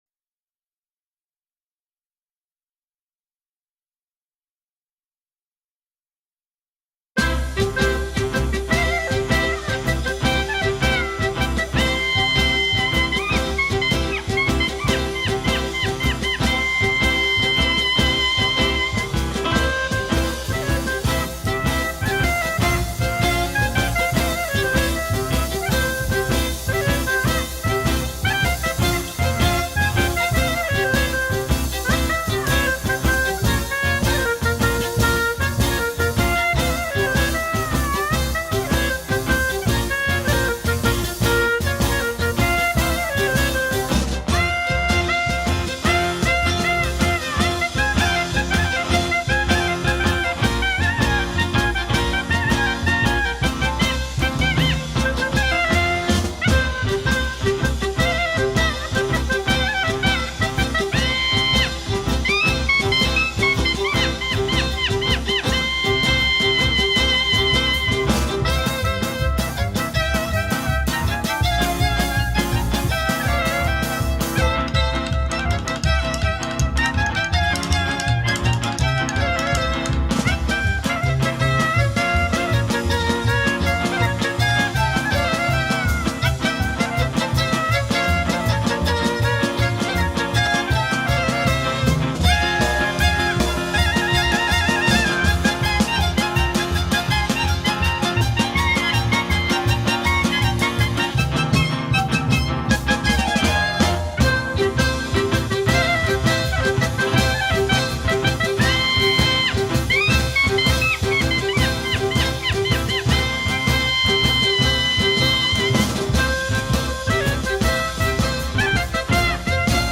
hava-nagila-in-the-style-of-traditional-karaoke-video-track-with-scrolling-lyrics
hava-nagila-in-the-style-of-traditional-karaoke-video-track-with-scrolling-lyrics.mp3